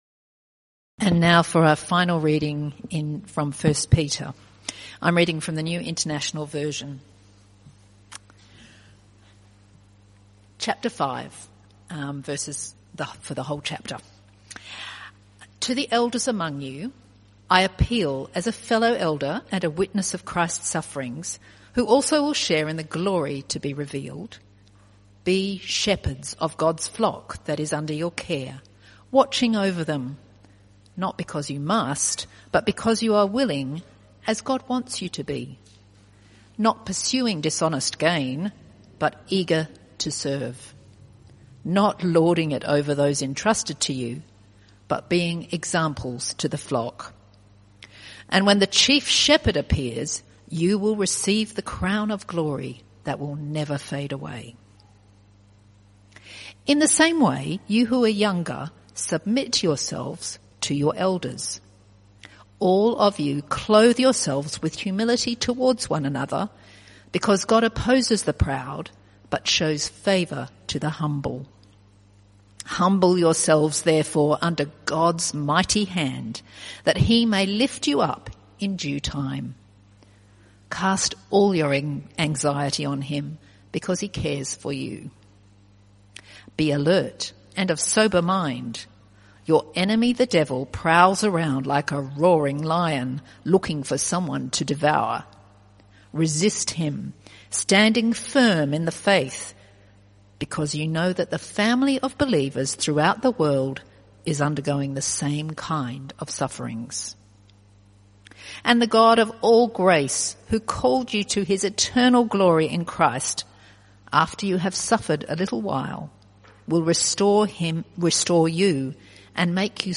CBC Service: 2 June 2024 Series
Type: Sermons